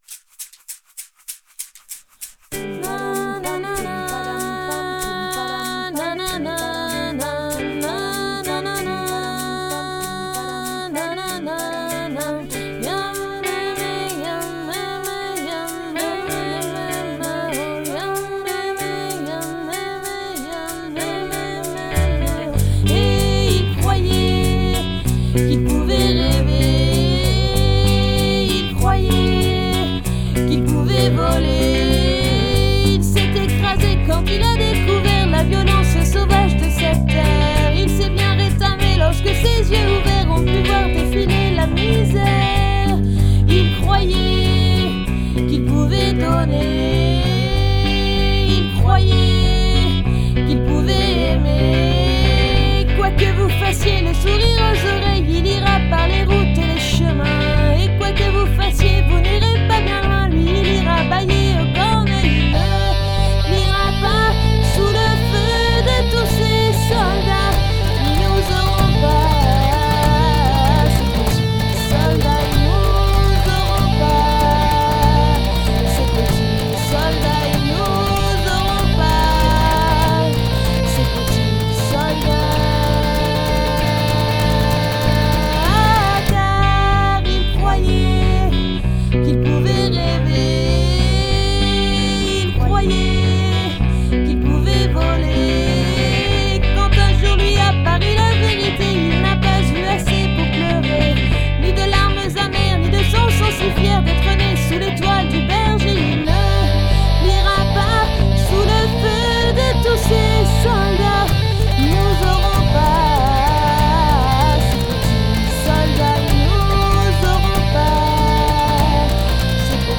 chant, guitare, bruitages
guitares, laud
guitare basse, percussions